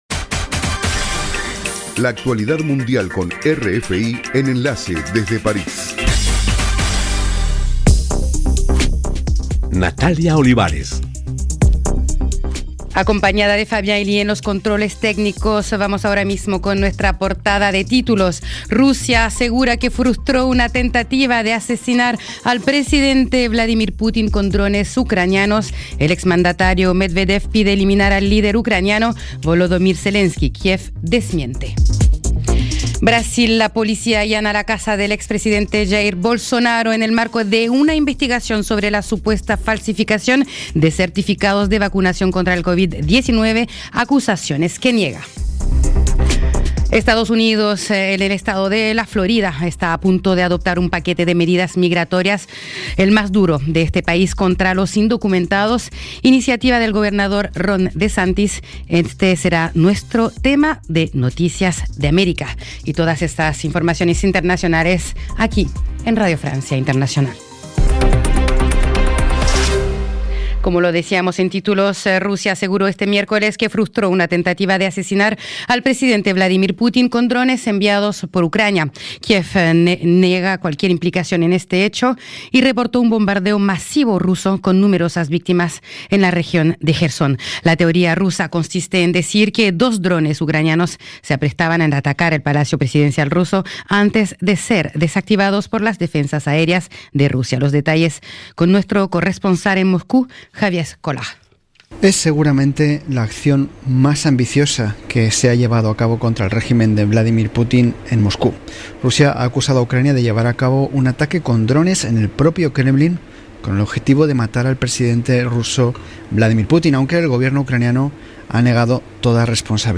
Programa: RFI - Noticiero de las 20:00 Hs.